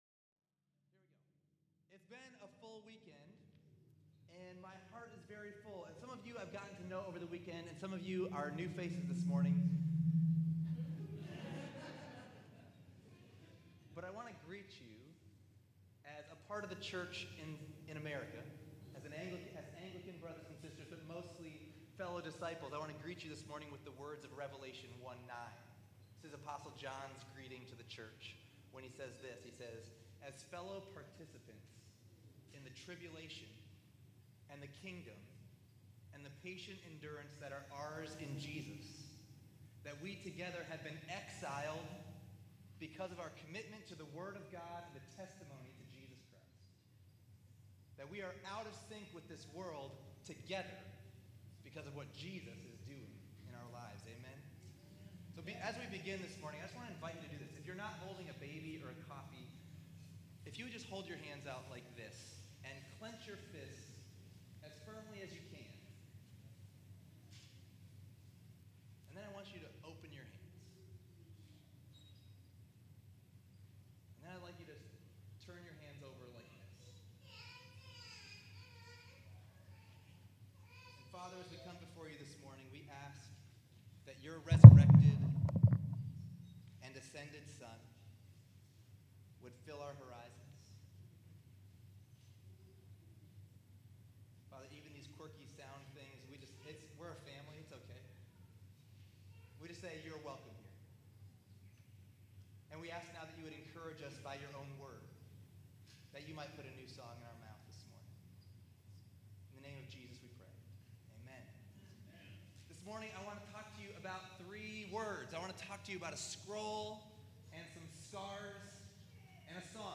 RESTORE Conference 2024 Current Sermon